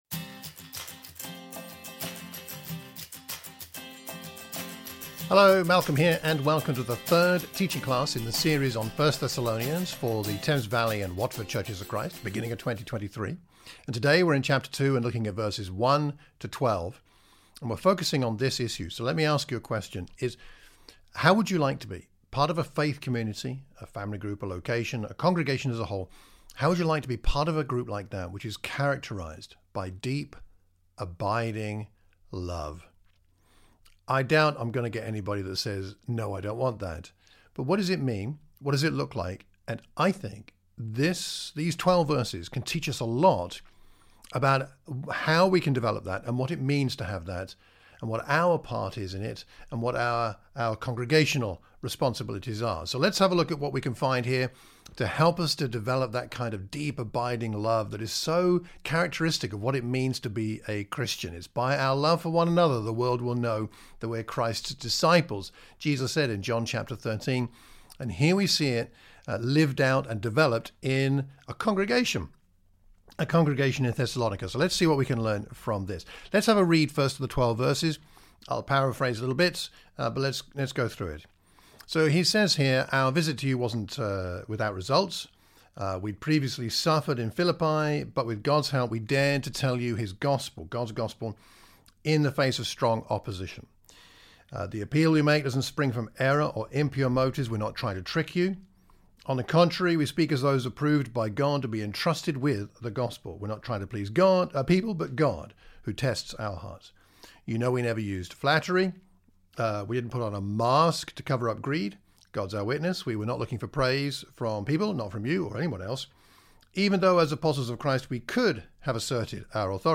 1 Thessalonians | Teaching Class | Chapter 2vv1-12